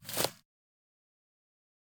footsteps-single-outdoors-002-07.ogg